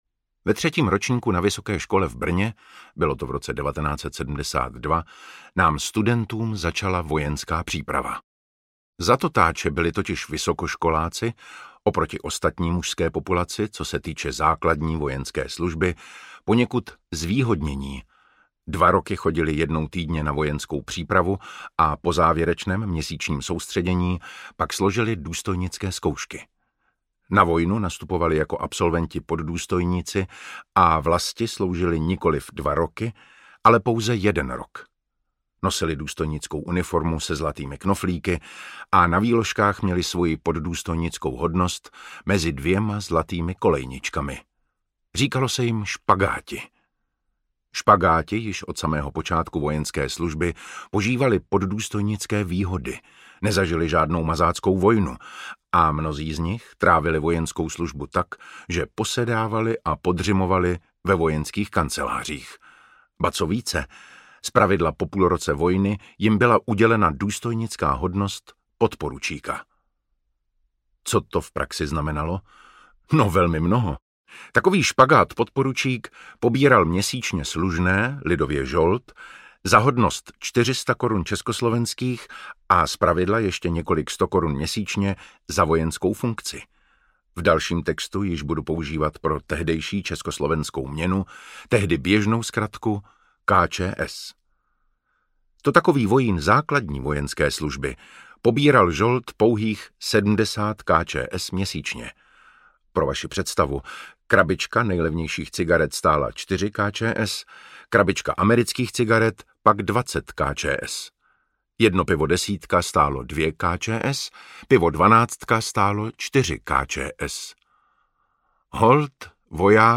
Bez spojení není velení a bez pakárny není vojna audiokniha
Ukázka z knihy
Čte David Matásek.
Vyrobilo studio Soundguru.
• InterpretDavid Matásek